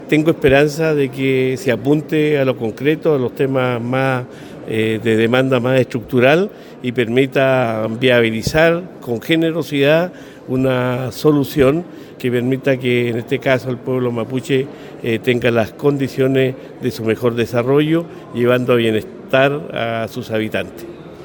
El gobernador de Los Ríos, Luis Cuvertino, estuvo presente en la ceremonia efectuada en el Palacio de La Moneda y aunque aún no conocía en detalle el contenido del informe, espera que contenga soluciones concretas a las demandas del pueblo mapuche.